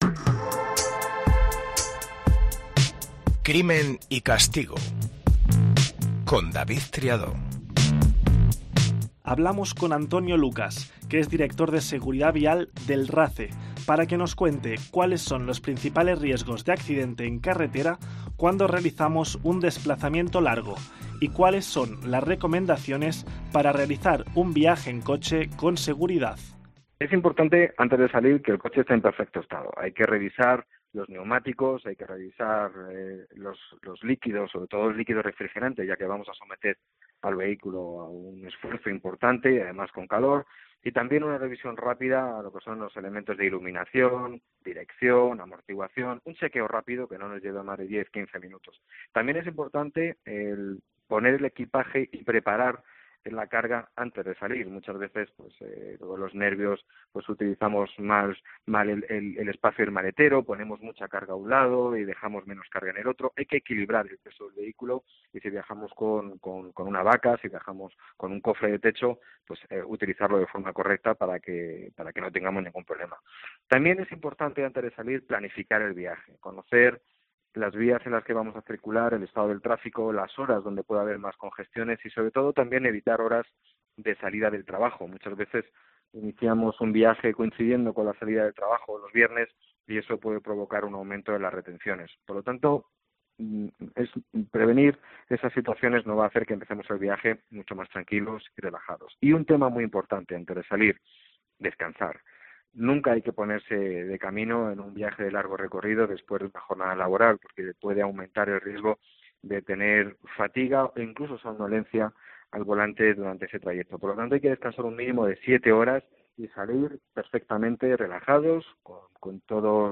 Lo hace en un formato dinámico, fresco, cercano, y con entrevistas a los implicados e intervenciones de expertos.